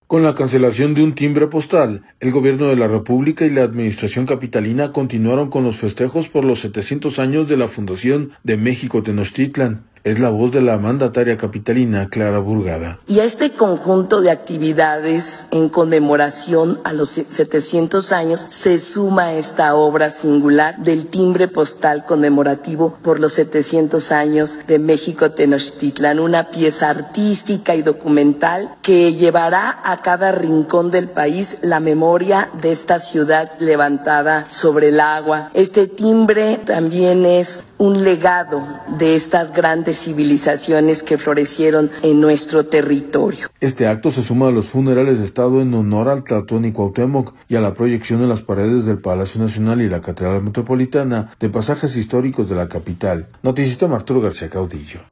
Con la cancelación de un timbre postal, el Gobierno de la República y la administración capitalina continuaron con los festejos por los 700 años de la fundación de México-Tenochtitlán. Es la voz de la mandataria capitalina, Clara Brugada.